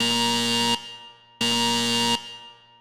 Alarm[loop].wav